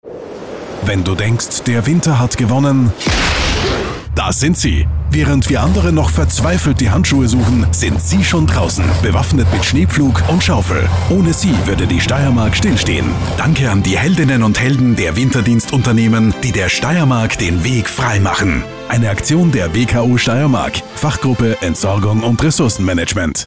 Hörfunkspots auf Antenne Steiermark würdigen wertvolle Arbeit.